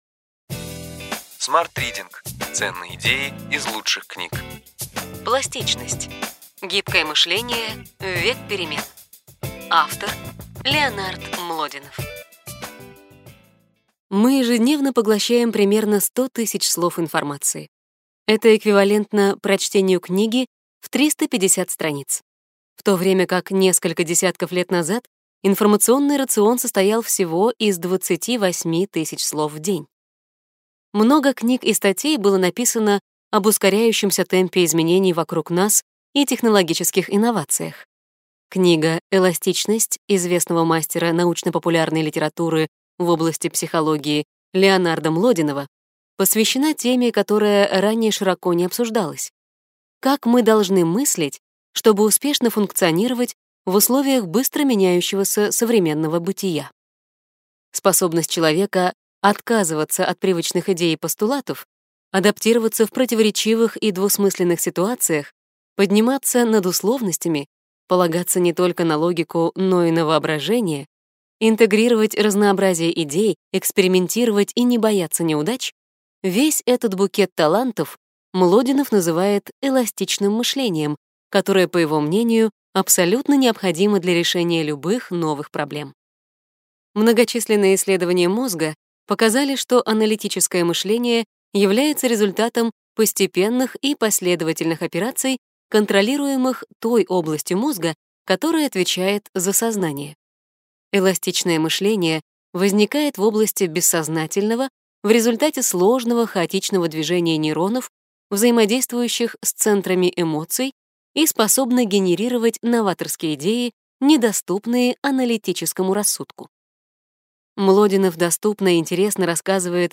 Аудиокнига Ключевые идеи книги: Пластичность. Гибкое мышление в век перемен.